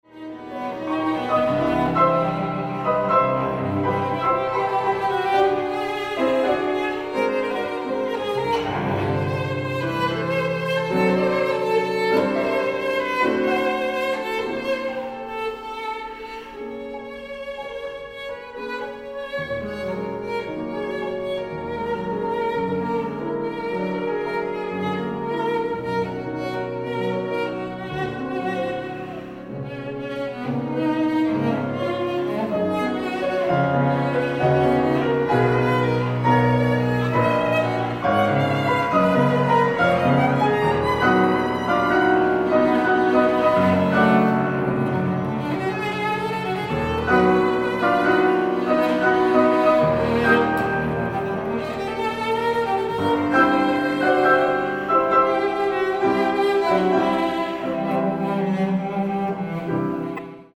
大提琴
鋼琴
使用骨董真空管麥克風錄音，並以自製的真空管混音台混音